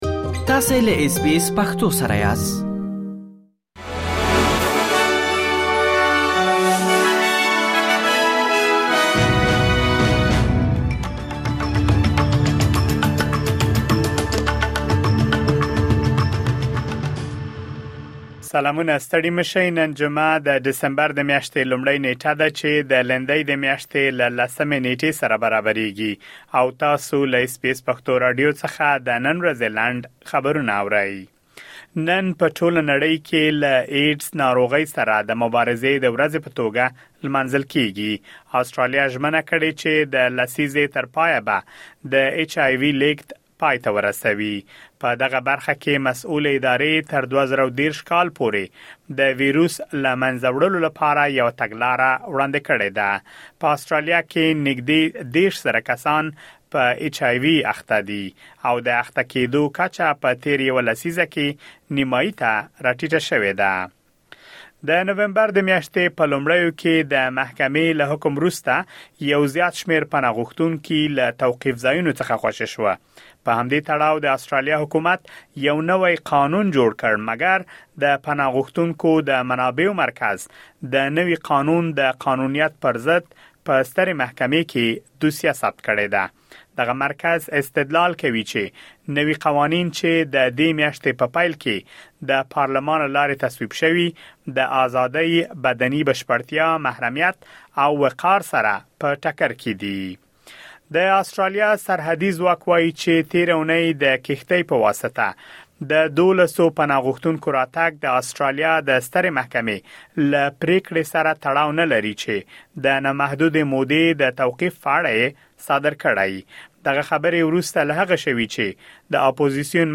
د اس بي اس پښتو راډیو د نن ورځې لنډ خبرونه | ۱۱ جنوري ۲۰۲۴